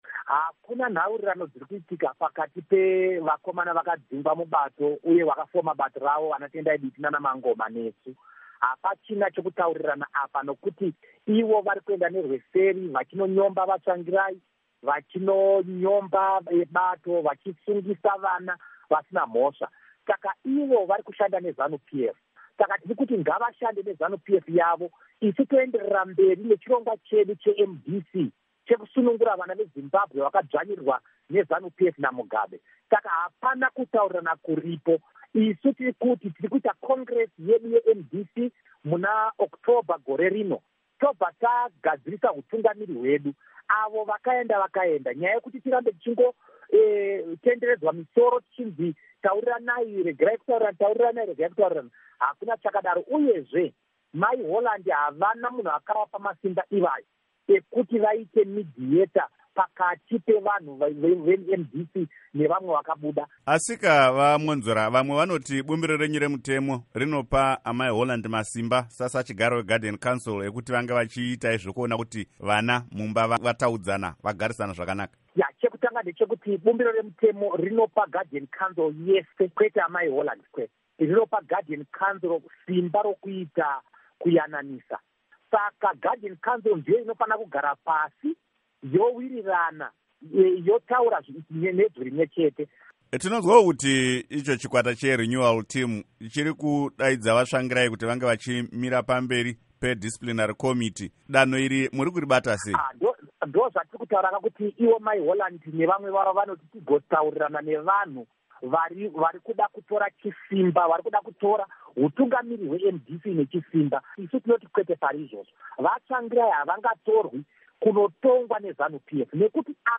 Hurukuro naVaDouglas Mwonzora